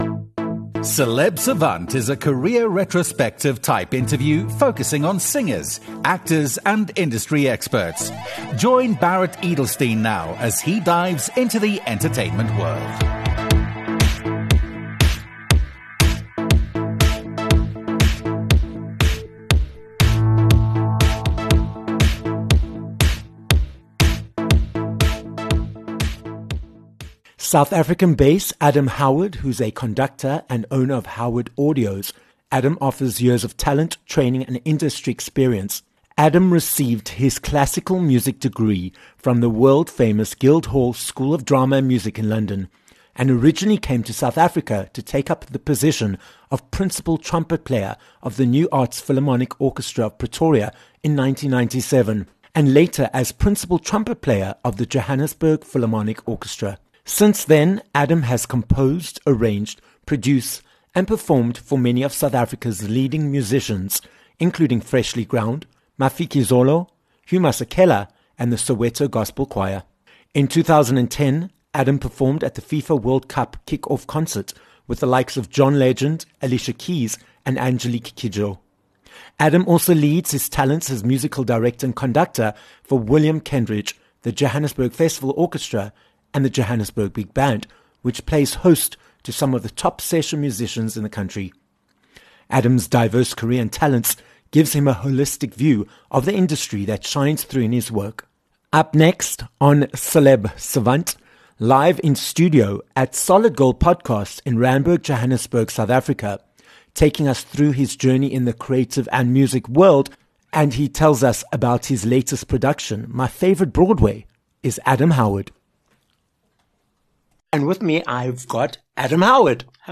This episode of Celeb Savant was recorded live in studio at Solid Gold Podcasts in Johannesburg, South Africa.